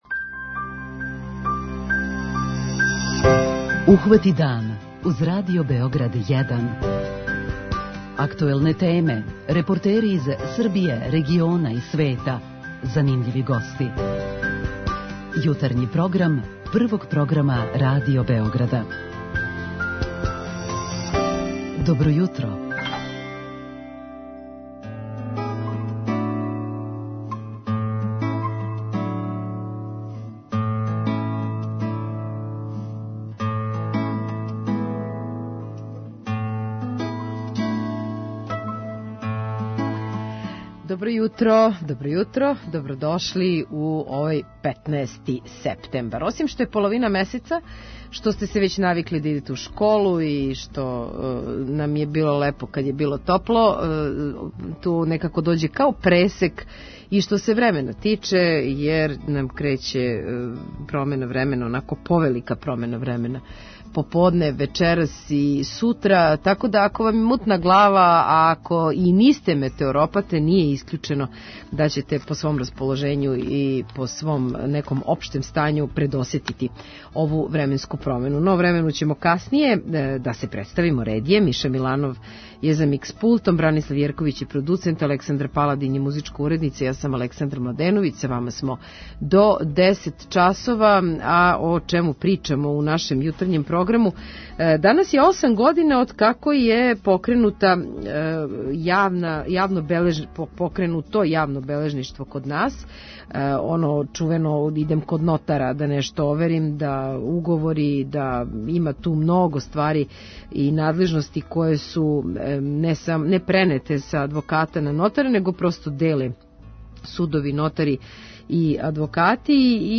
Јутарњи програм Радио Београда 1!